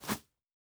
Jump Step Grass B.wav